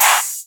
chant+oh(1).wav